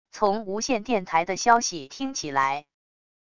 从无线电台的消息听起来wav音频